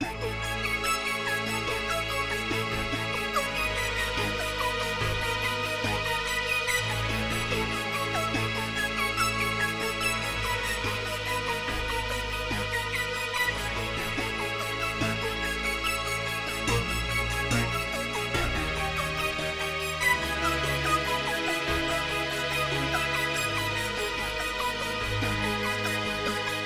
05 synth arp C.wav